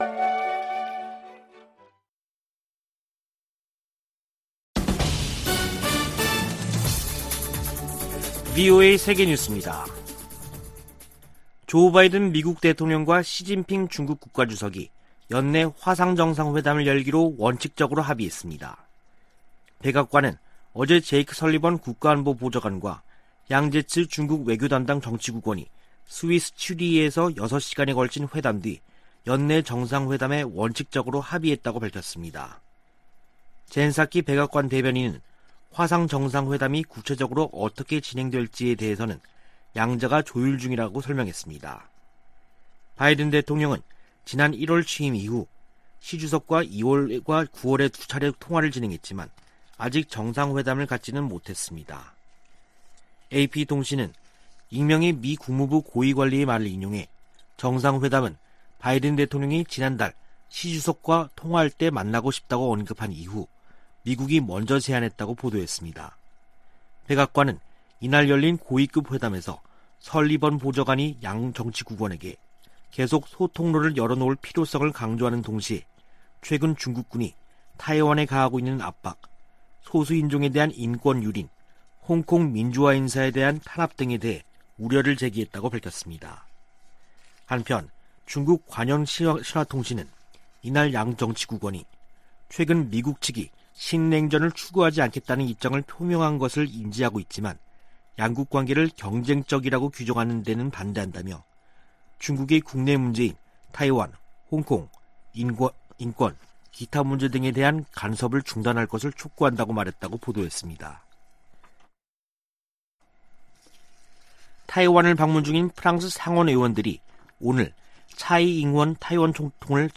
VOA 한국어 간판 뉴스 프로그램 '뉴스 투데이', 2021년 10월 7일 3부 방송입니다. 북한이 영변 핵시설 내 우라늄 농축공장 확장 공사를 계속하고 있는 것으로 파악됐습니다.